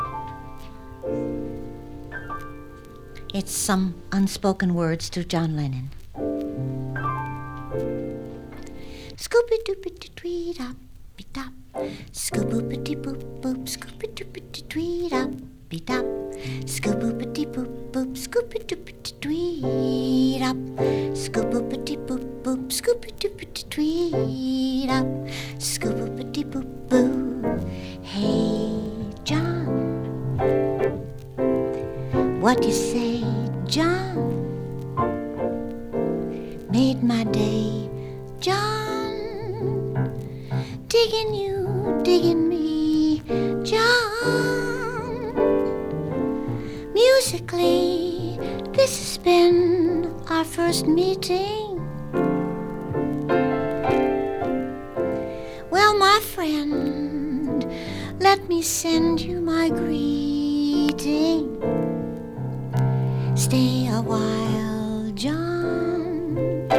キュートなピアノ弾き語りガーリィSSWもの、として聴いてしまっても全然OKなレコードです。